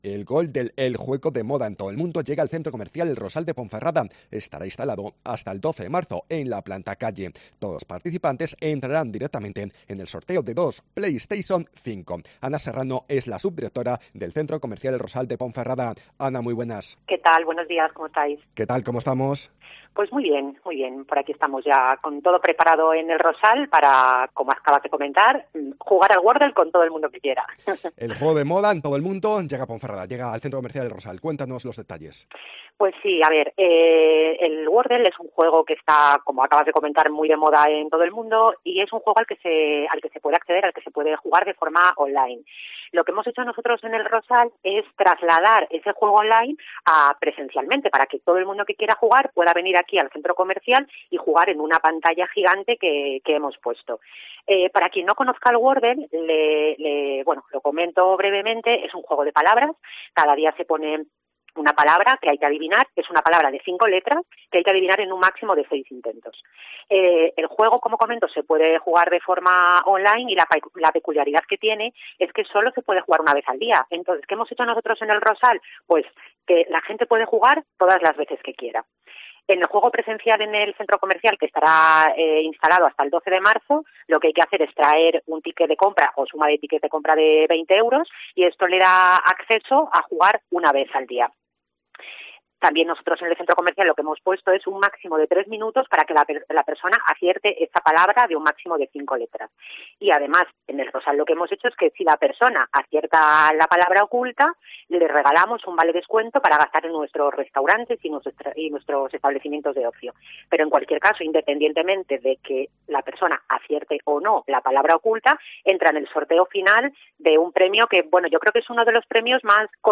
SOCIEDAD-MEDIODÍA COPE